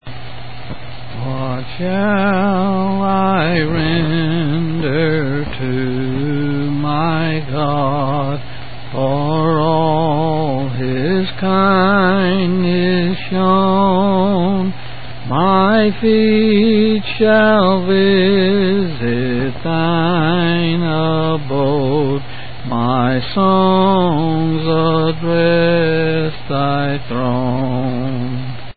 C. M.